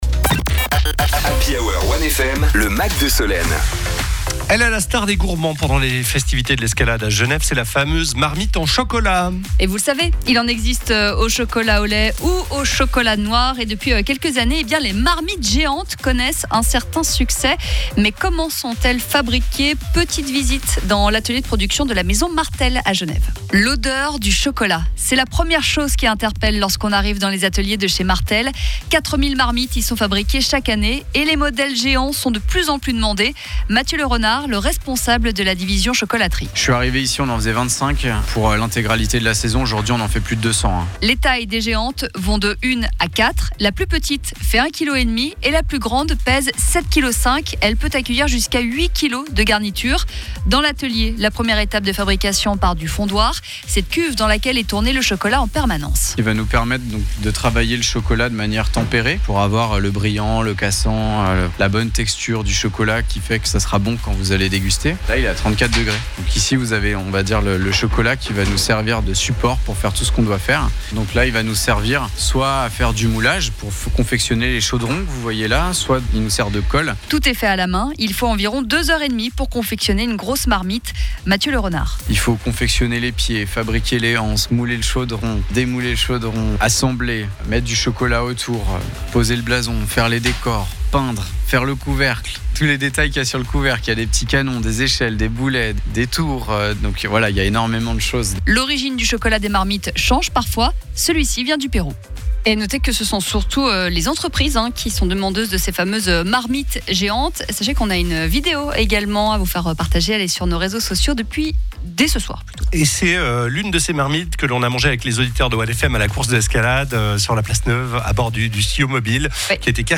Reportage dans l’atelier de production de la maison Martel à Genève: